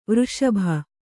♪ vřṣabha